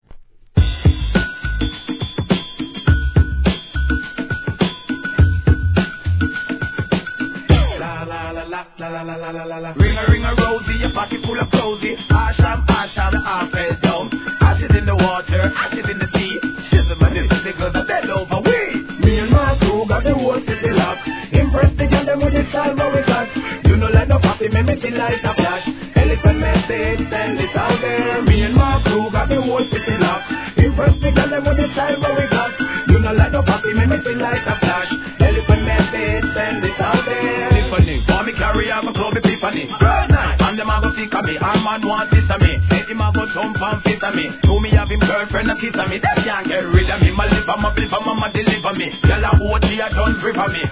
HIP HOP/R&B
HIP HOP TRACKとREGGAEのアカペラをブレンドした使い勝手の良い一枚！！